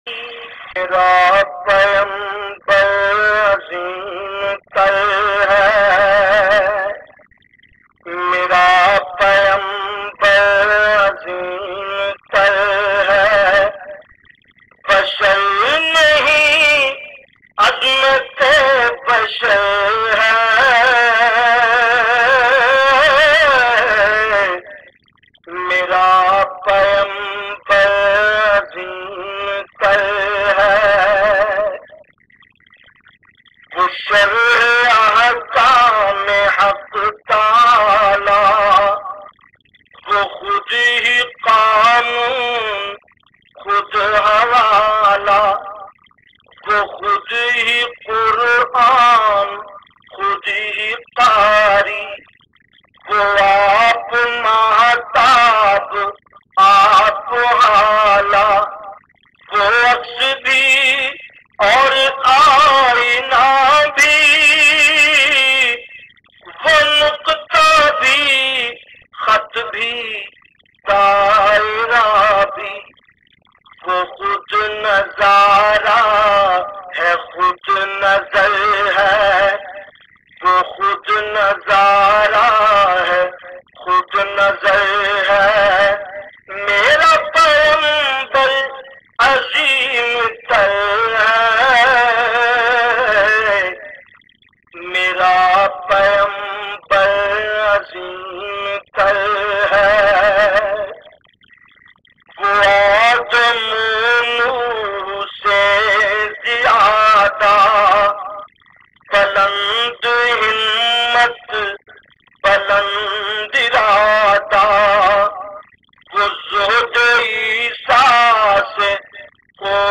Naat Lyrics